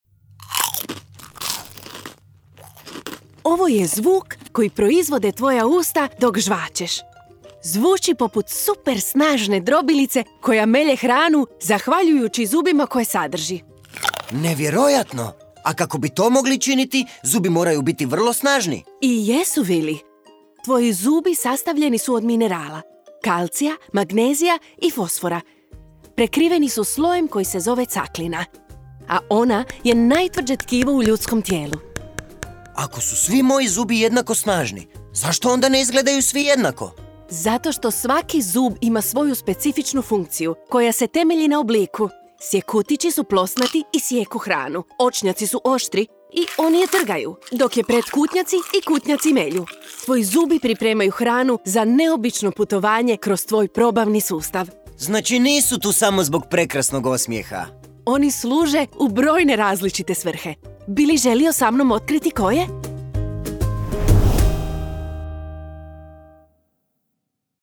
Poslušajte kako zvuče vaša usta dok žvačete